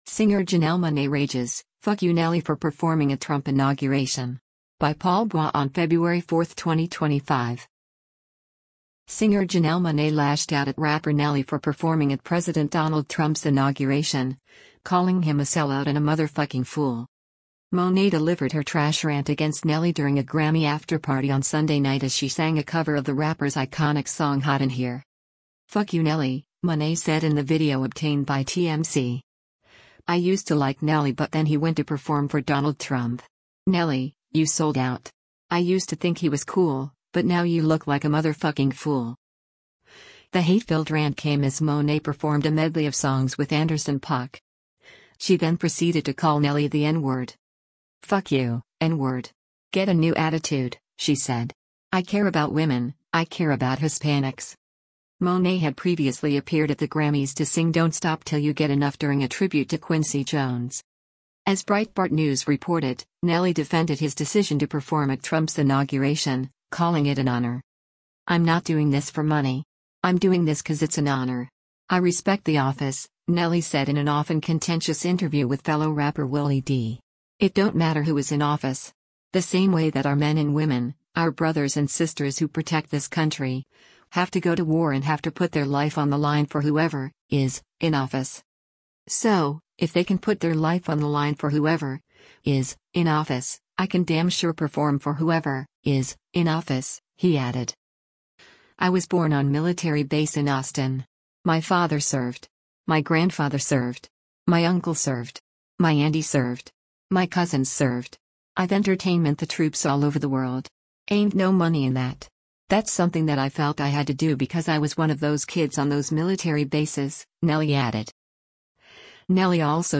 during a Grammy afterparty on Sunday night